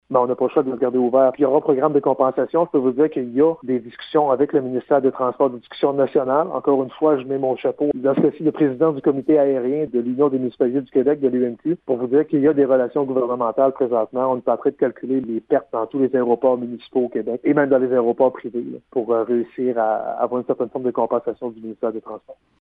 Le maire de Gaspé, Daniel Côté :